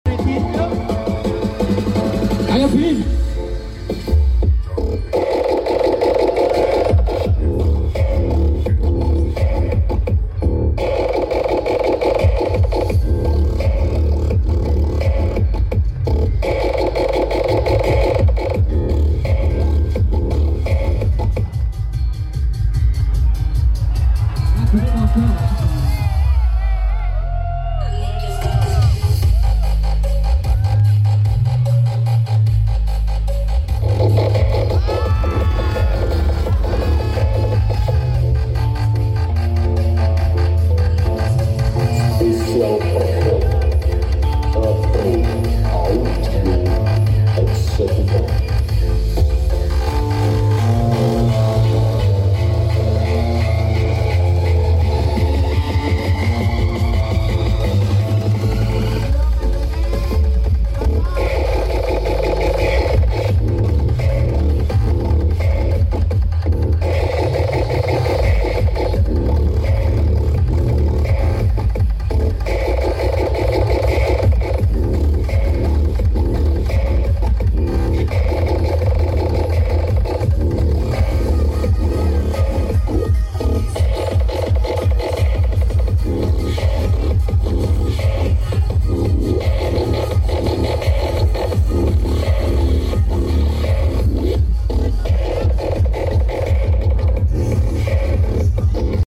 jingle andalan, toa mesjid mburub